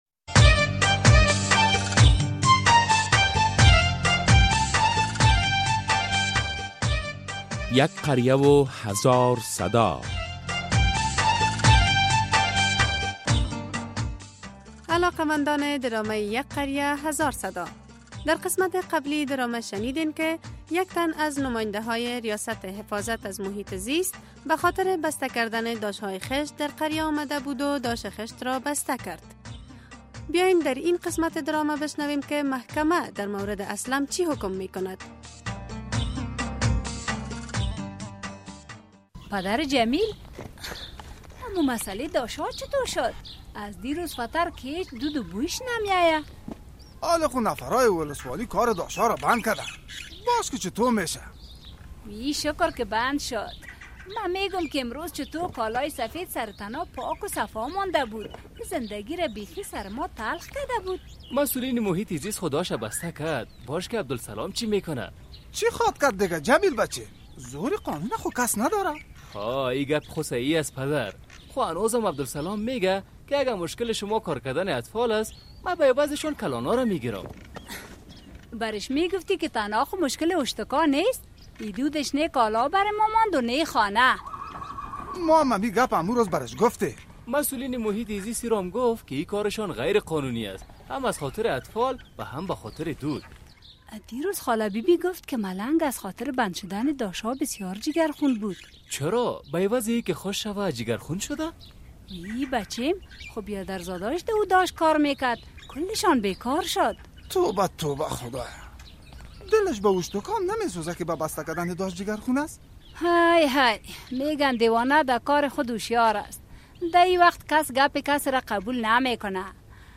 در این درامه که موضوعات مختلف مدنی، دینی، اخلاقی، اجتماعی و حقوقی بیان می گردد هر هفته به روز های دوشنبه ساعت ۳:۳۰ عصر از رادیو آزادی نشر می گردد...